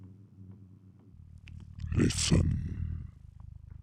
• demonic techno voice "listen".wav
Changing the pitch and transient for a studio recorded voice (recorded with Steinberg ST66), to sound demonic/robotic.